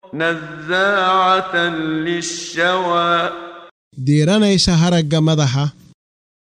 Waa Akhrin Codeed Af Soomaali ah ee Macaanida Suuradda A-Macaarij ( Wadooyinka samada ) oo u kala Qaybsan Aayado ahaan ayna la Socoto Akhrinta Qaariga Sheekh Muxammad Siddiiq Al-Manshaawi.